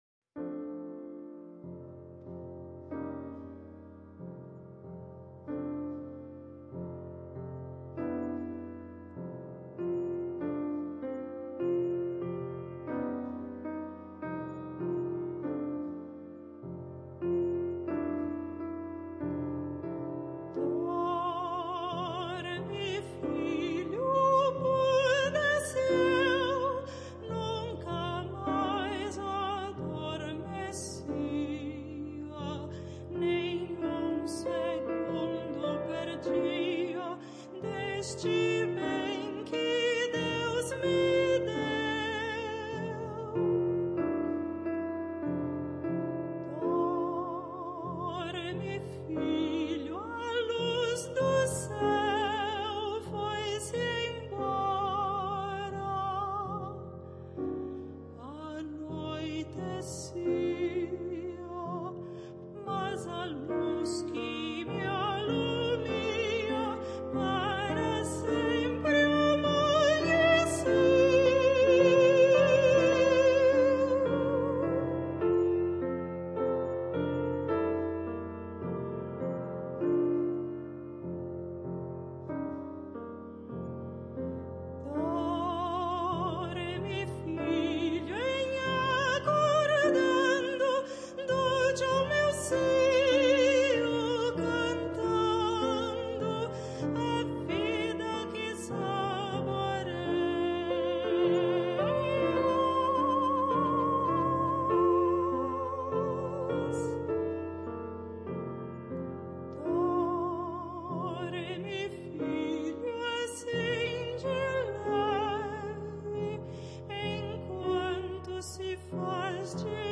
soprano
piano.